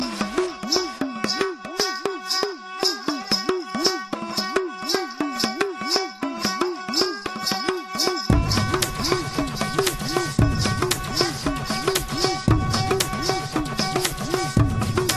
Description: Sarangi folk